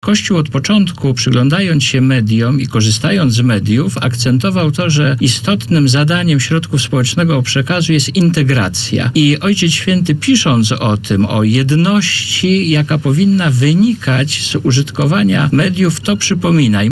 mówi na antenie Radia Warszawa